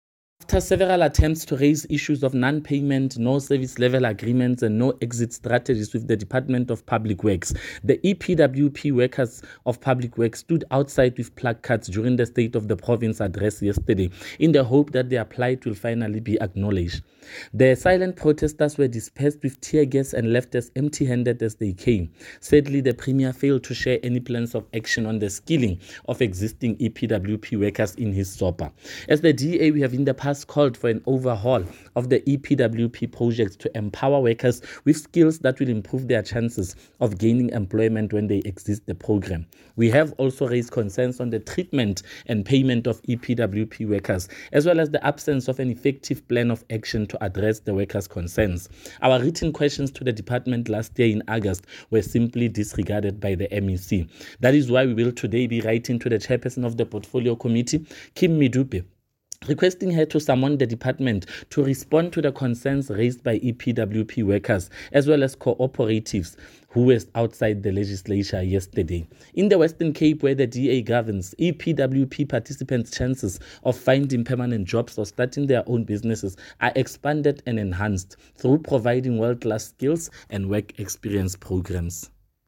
Note to Editors: Find attached soundbites in
Sesotho by DA North West Spokesperson on Public Works and Roads, Freddy Sonakile.